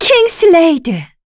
Yuria-Vox_Kingsraid_kr.wav